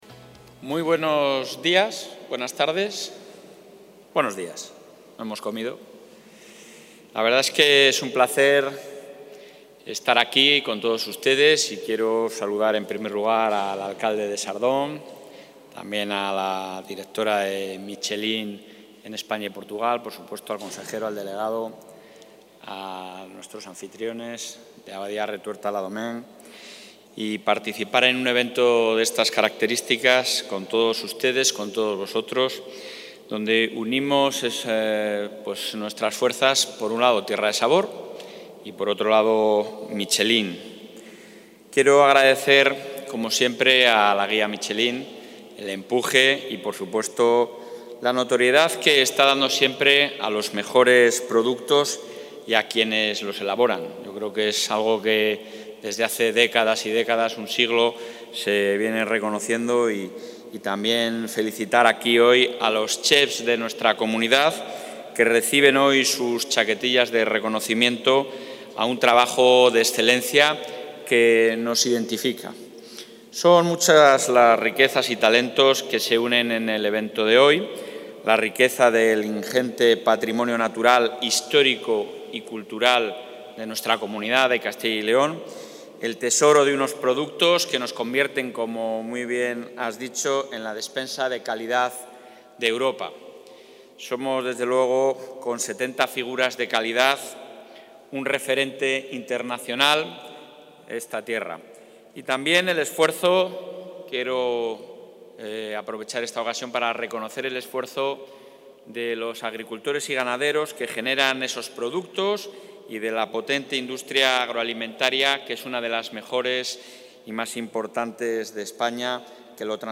Intervención del presidente.